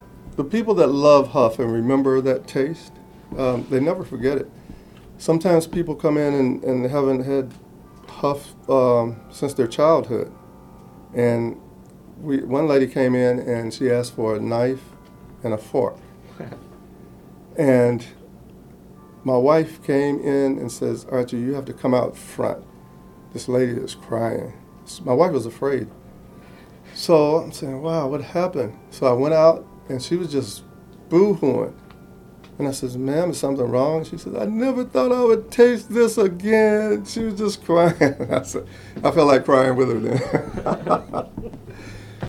Cleveland Regional Oral History Collection